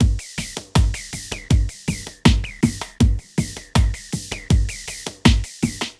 DRUMLOOP044_AMBNT_160_X_SC3(L).wav